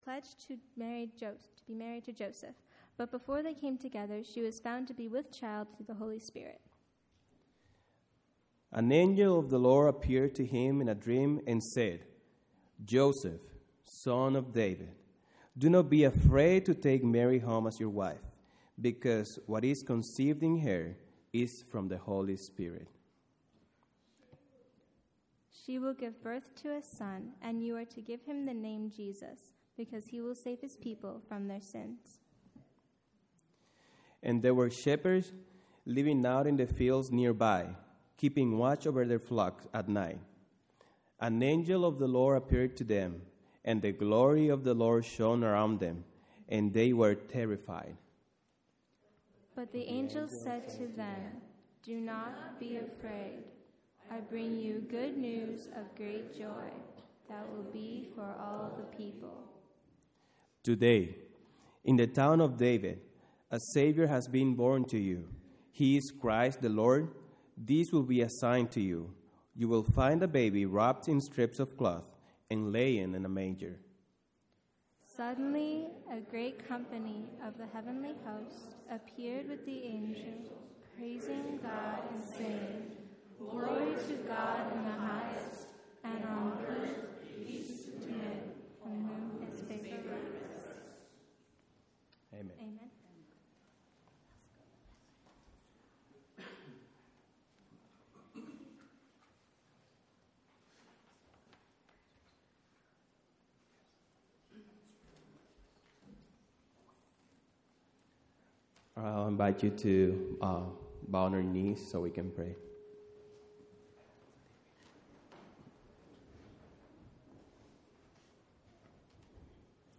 Seventh-day Adventist Church
Cantata on 2024-02-17 - The Spoken Word